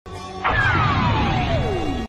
Play, download and share shinra tensei sound effect original sound button!!!!